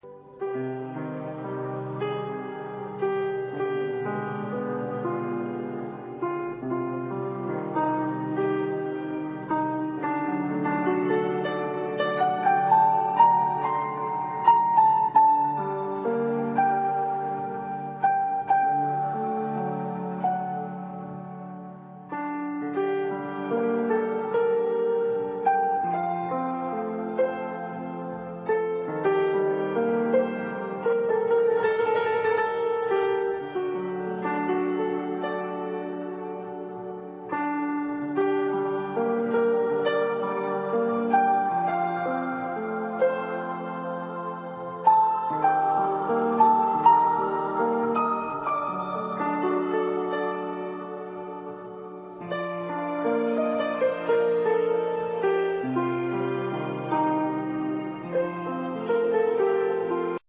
Passionate and Heart-felt music.